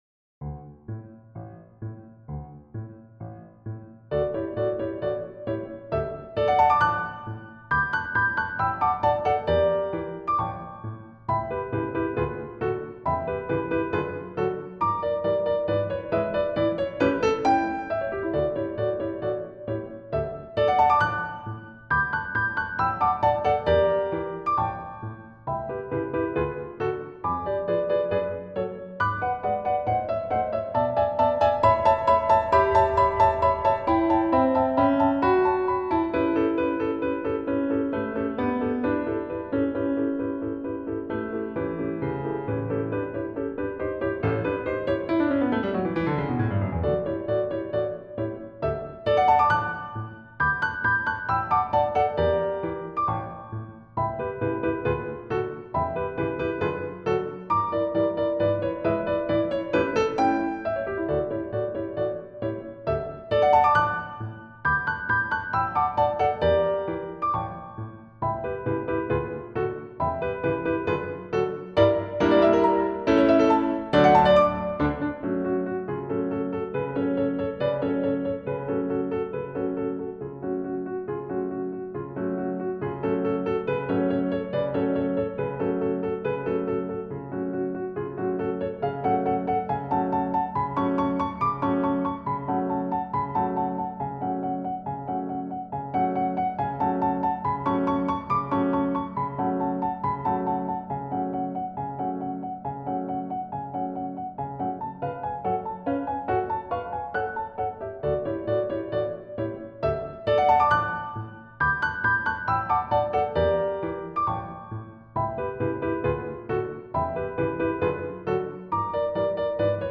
ピアノ MIDI・MP3ファイルPiano MIDI・MP3 Files